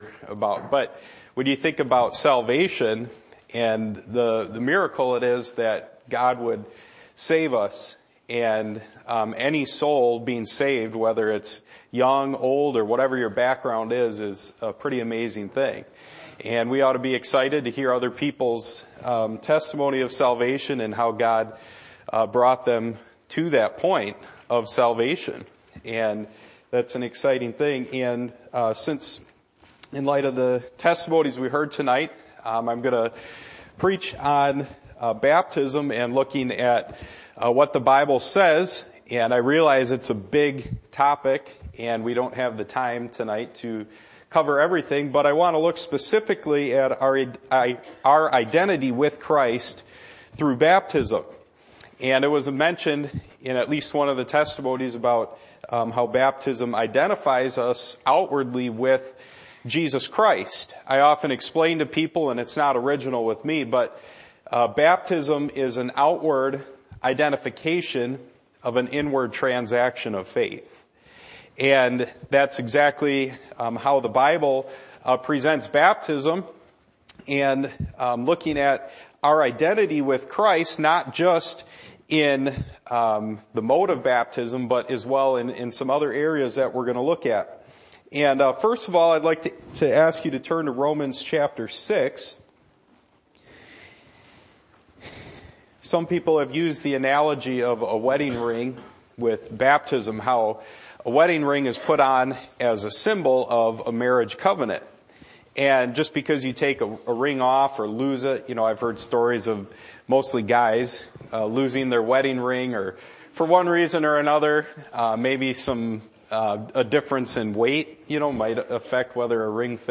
Passage: Romans 6:3-4 Service Type: Midweek Meeting %todo_render% « The Providence Of God Change of Master